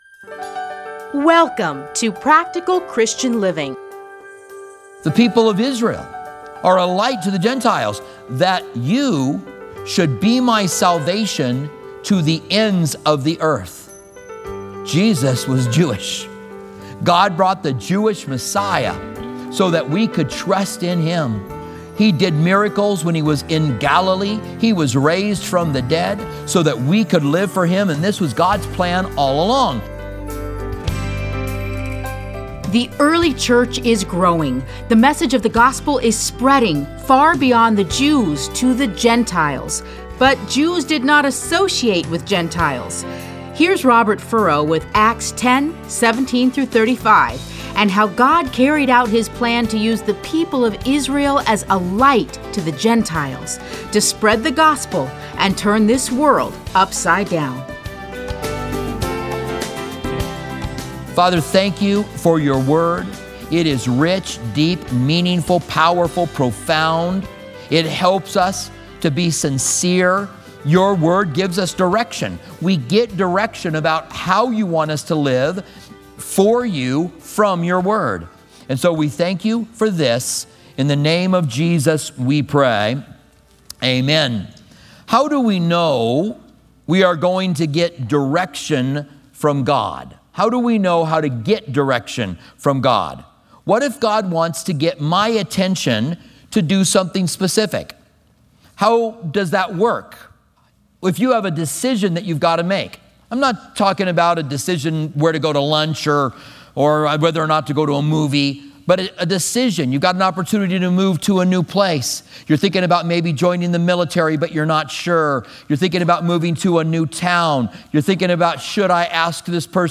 Listen to a teaching from Acts 10:17-35.